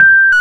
Index of /90_sSampleCDs/Roland LCDP10 Keys of the 60s and 70s 2/PNO_Rhodes/PNO_73 Suitcase
PNO G5 P  0H.wav